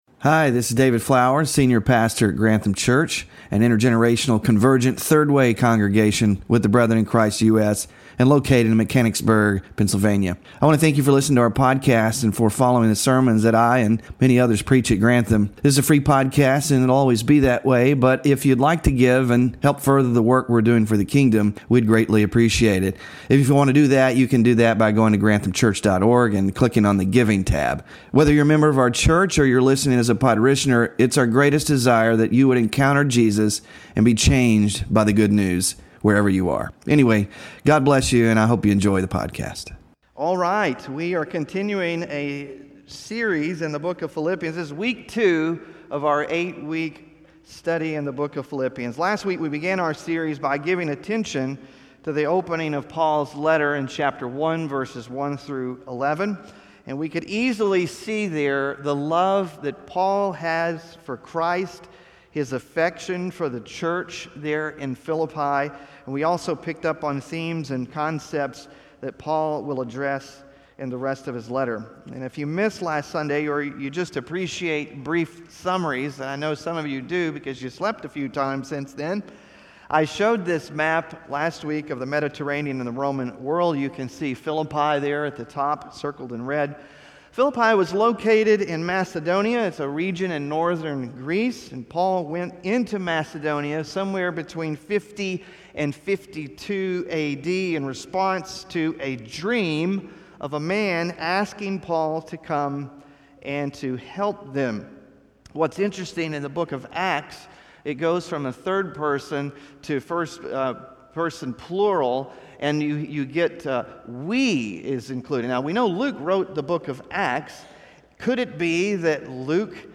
WORSHIP RESOURCES PHILIPPIANS SERMON SLIDES (2ND of 8 IN SERIES) SMALL GROUP DISCUSSION QUESTIONS (8-18-24) BULLETIN WITH BAPTISM TESTIMONIES (8-18-24)